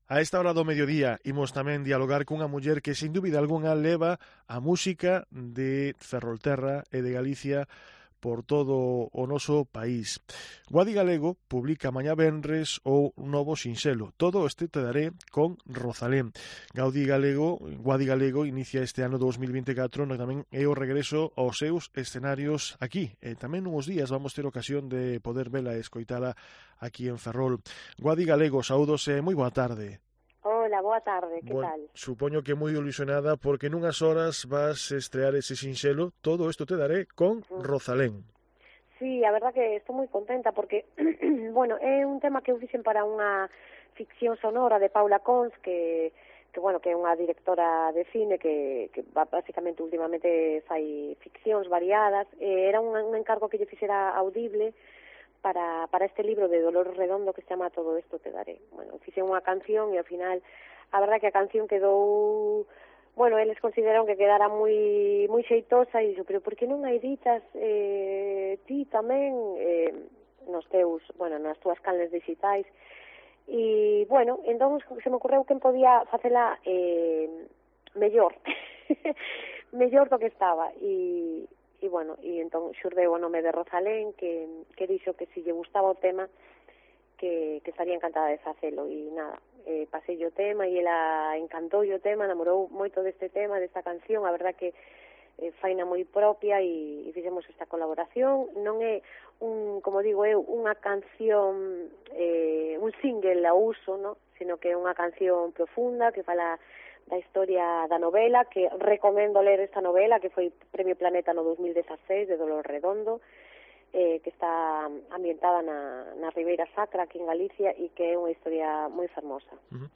Entrevista a Guadi Galego en COPE Ferrol